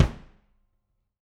Index of /musicradar/Kicks/Loose Kick
CYCdh_LooseKick-07.wav